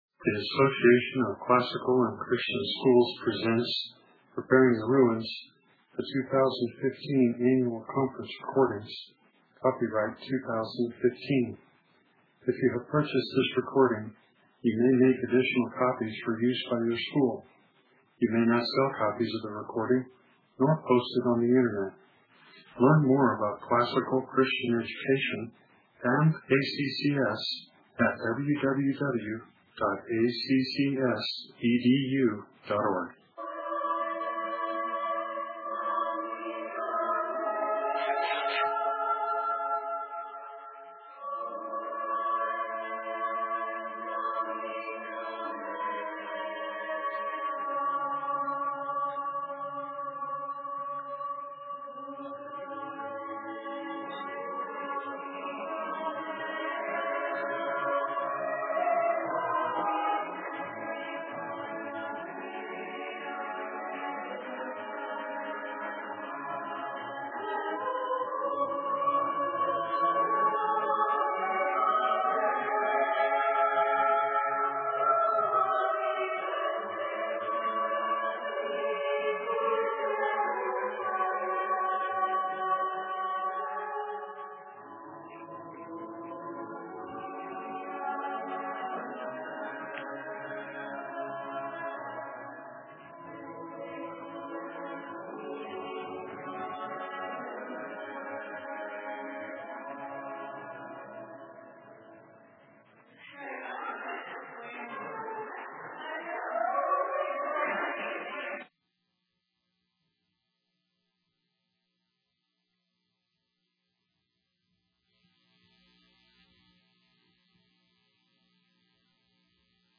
2015 Practicum Talk, 1:24:05, All Grade Levels, Culture & Faith
Dec 19, 2018 | All Grade Levels, Conference Talks, Culture & Faith, Library, Practicum Talk | 0 comments